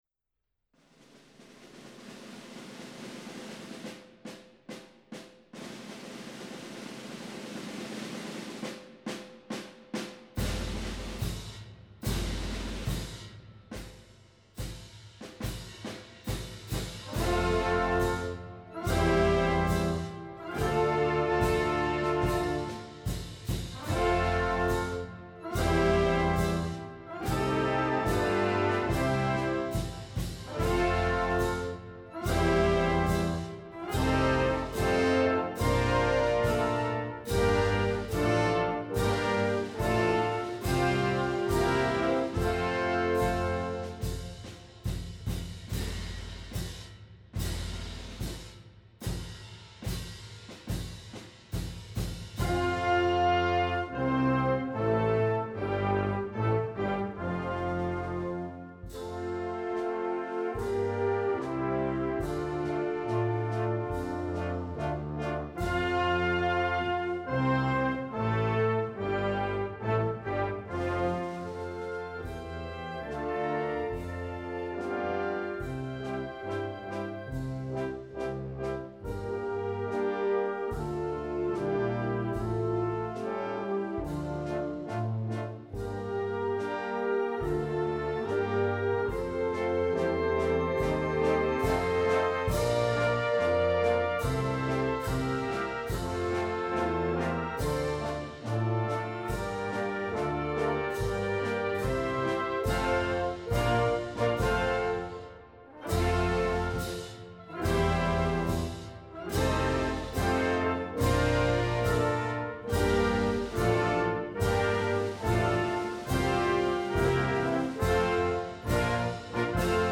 Gattung: Prozessionsmarsch
Besetzung: Blasorchester